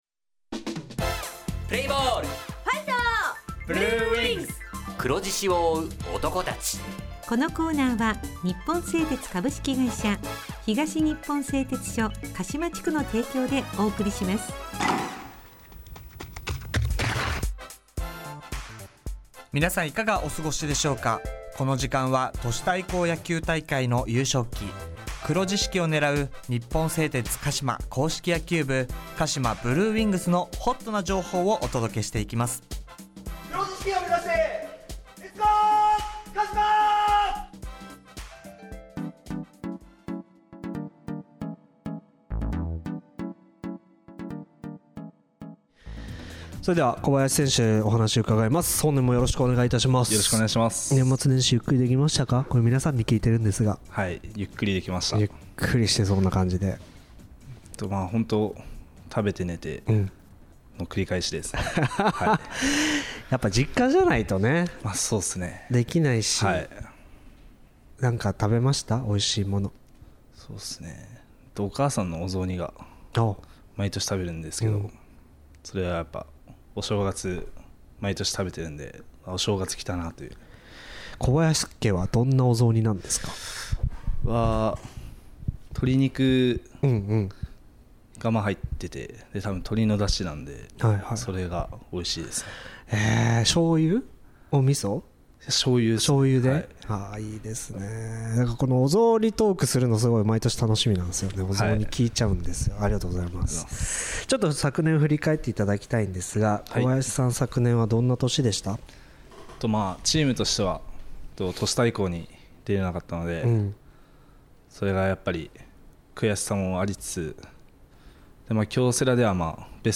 インタビュー
地元ＦＭ放送局「エフエムかしま」にて鹿島硬式野球部の番組放送しています。